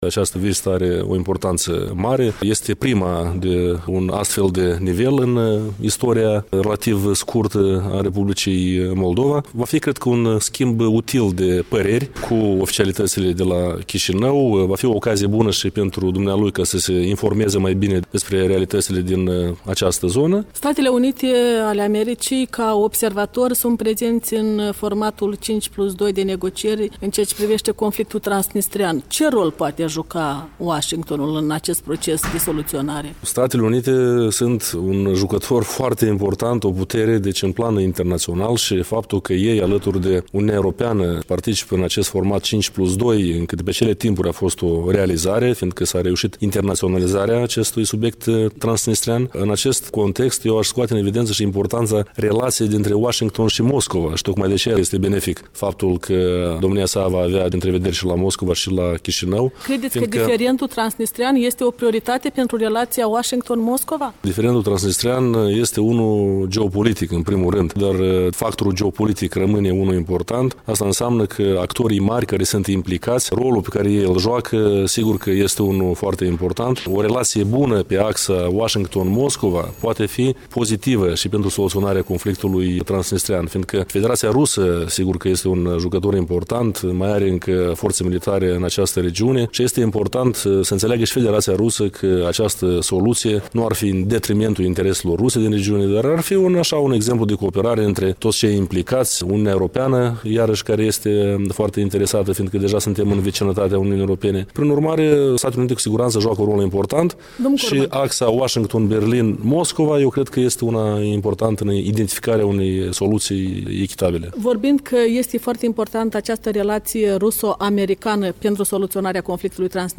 Igor Corman, președintele Comisiei de politică externă a Parlamentului